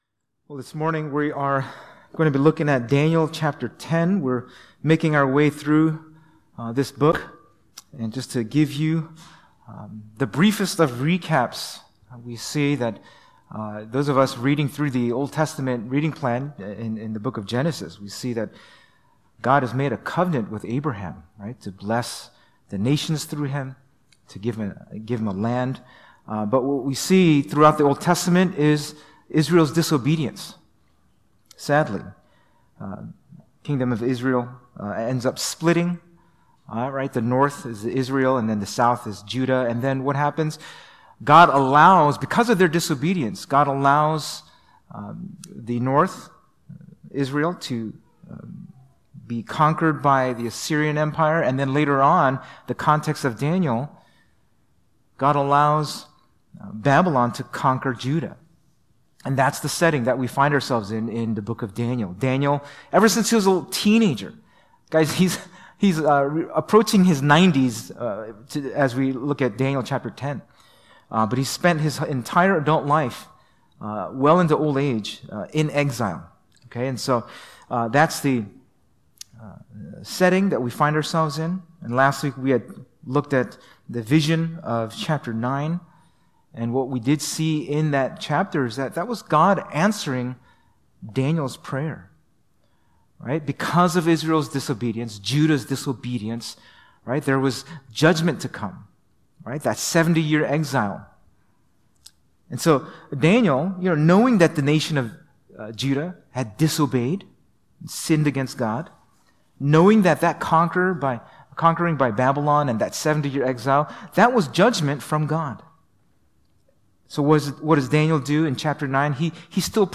February 4, 2024 (Sunday Service)